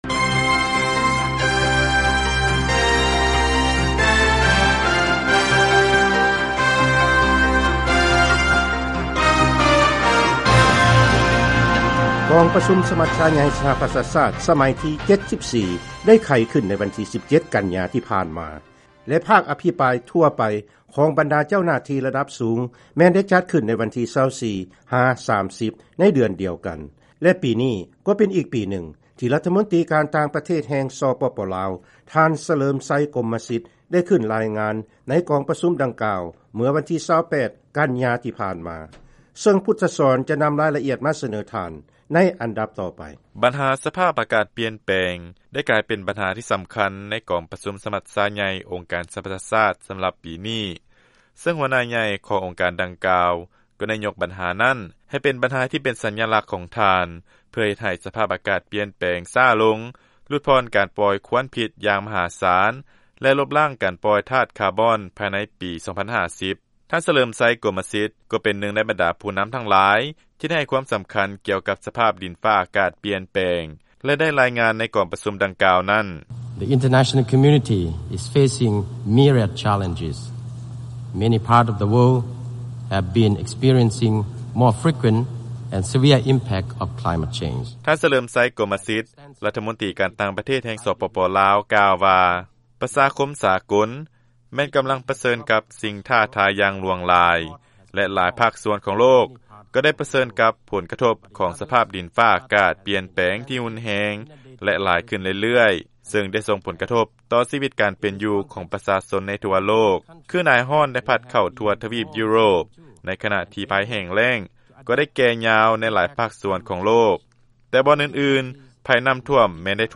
ຟັງລາຍງານພິເສດ: ທ່ານ ສະເຫຼີມໄຊ ກົມມະສິດ ຂຶ້ນກ່າວຄຳປາໄສ ທີ່ກອງປະຊຸມສະມັດຊາໃຫຍ່ ສປຊ